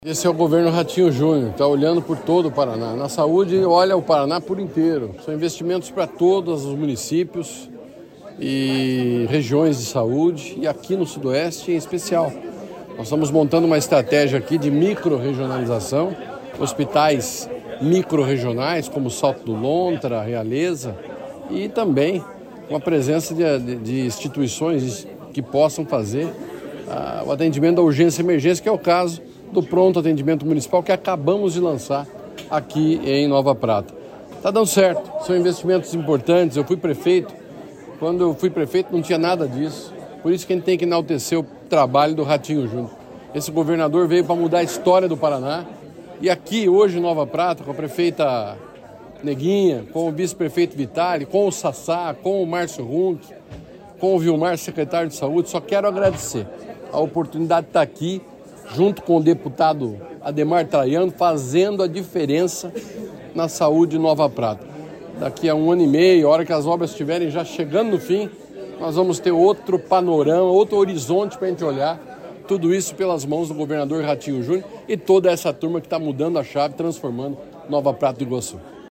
Sonora do secretário da Saúde, Beto Preto, sobre a liberação de 24 milhões para fortalecimento da saúde pública em cidades do Sudoeste